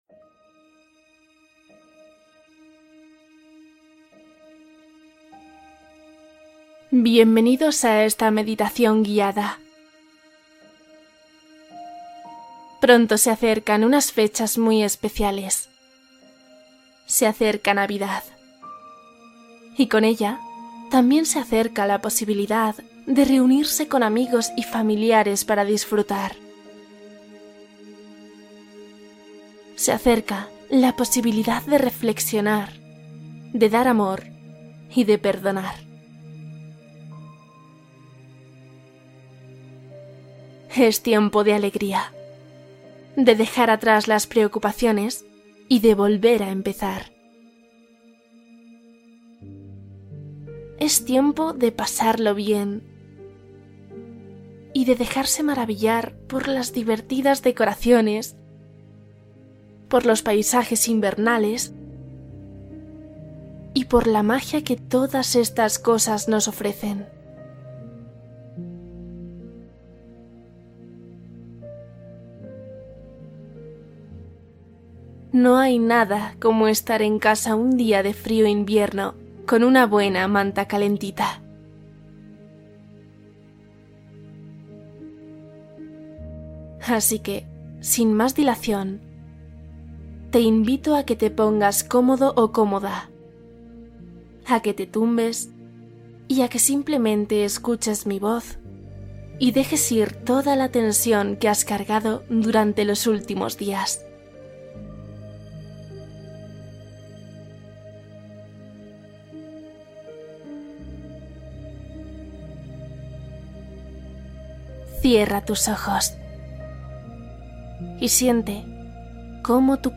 Cuento navideño para dormir: descanso y felicidad nocturna